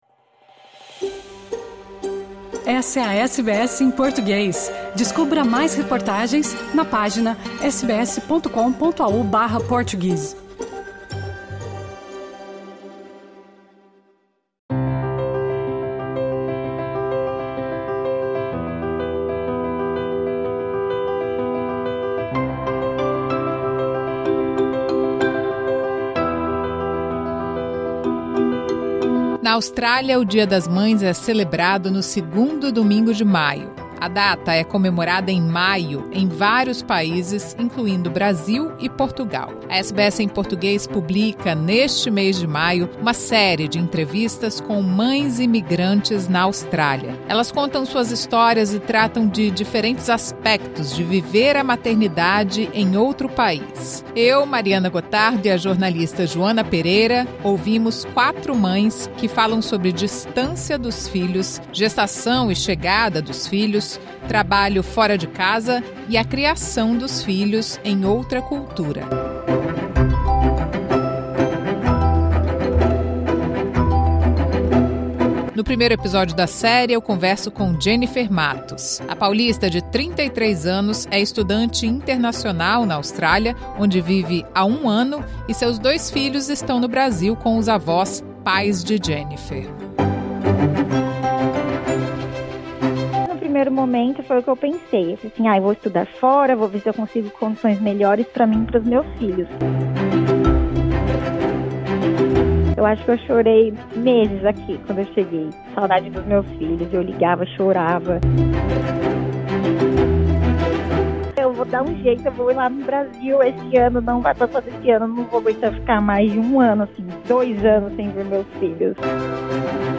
Esta entrevista faz parte de uma série da SBS em português com mães imigrantes na Austrália.